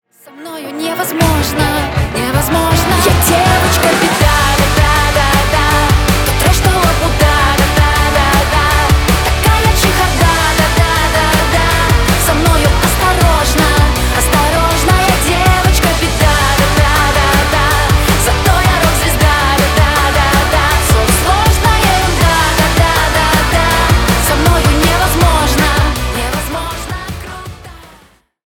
Рок Металл
весёлые